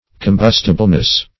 Combustibleness \Com*bus"ti*ble*ness\, n.
combustibleness.mp3